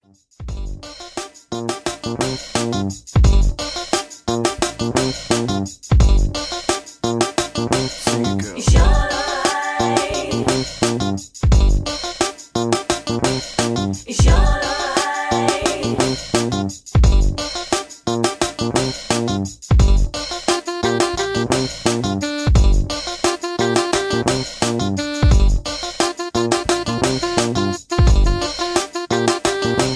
(Key-Gm) Karaoke Mp3 Backing Tracks
mp3 backing tracks